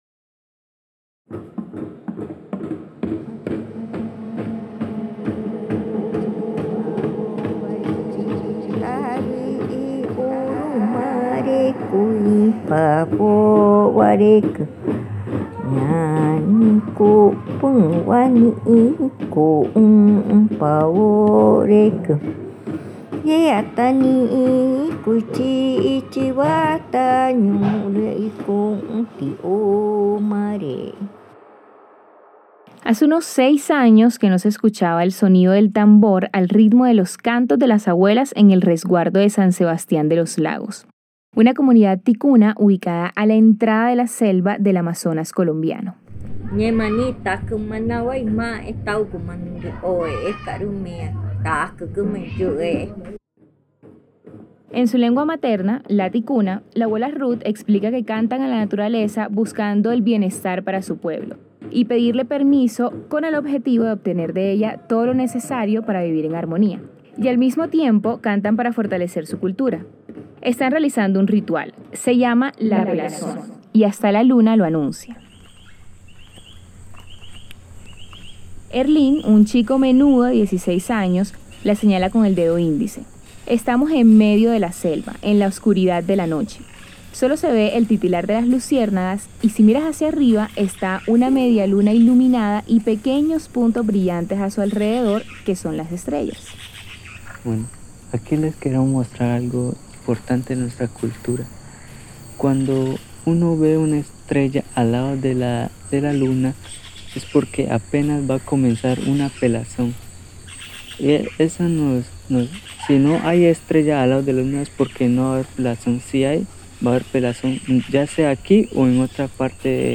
En el resguardo de San Sebastián de Los Lagos (Leticia), La pelazón es un ritual que permite construir la identidad indígena Ticuna. En este episodio suenan los tambores y los cantos de las abuelas para anunciar la menarquia de una niña.